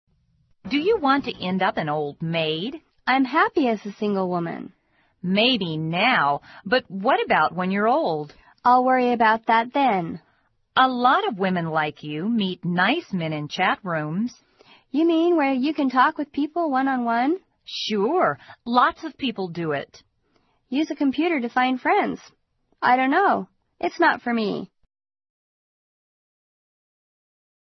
网络社交口语对话第6集：用电脑交朋友